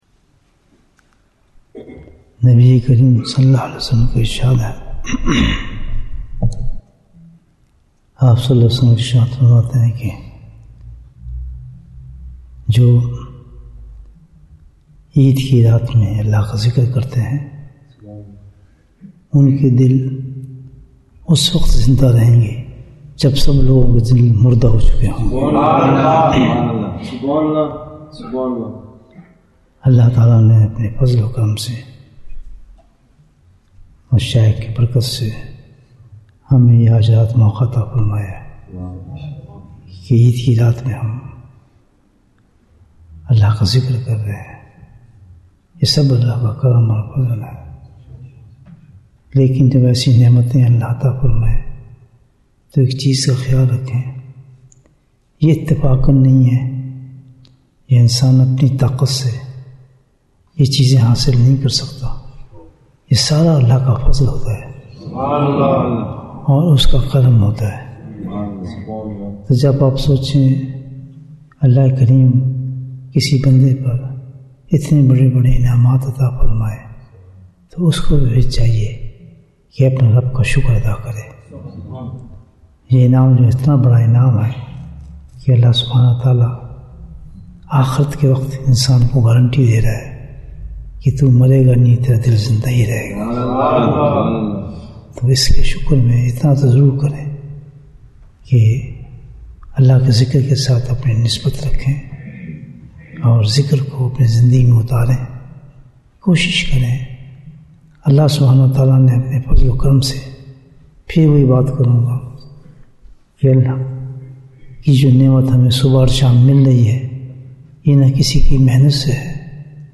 Bayan, 5 minutes